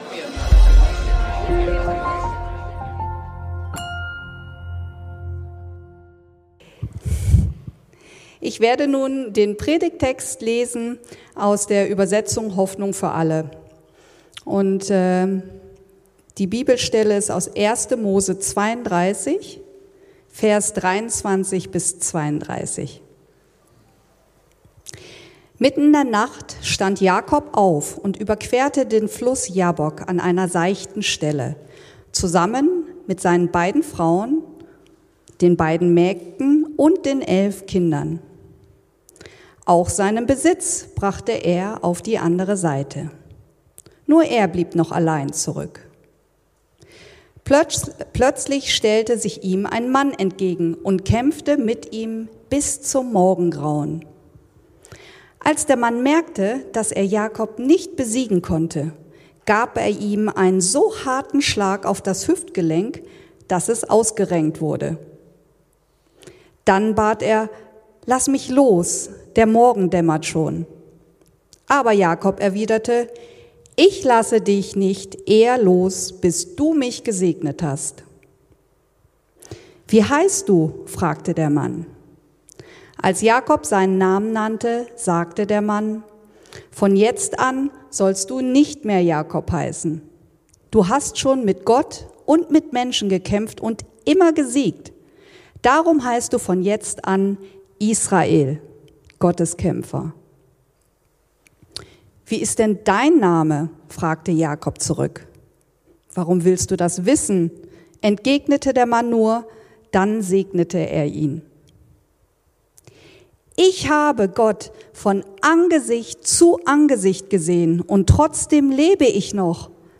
Der Kampf deines Lebens ~ Predigten der LUKAS GEMEINDE Podcast